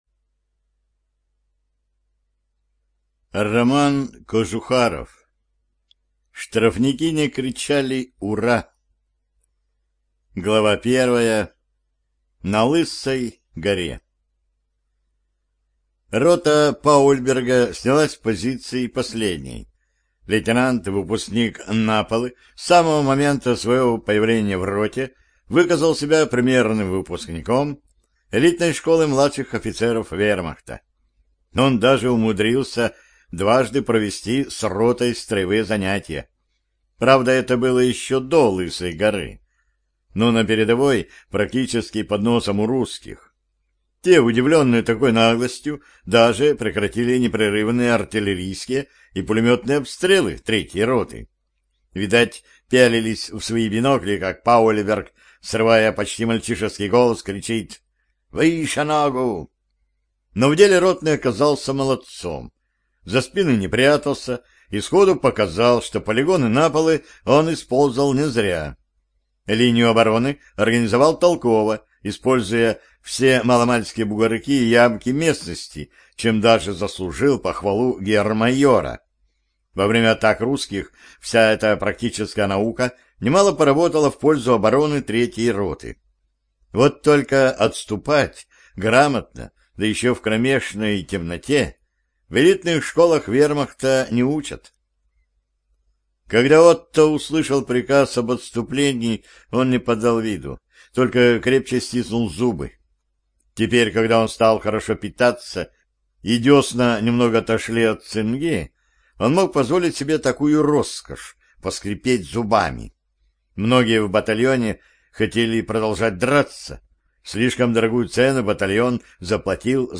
ЖанрВоенная литература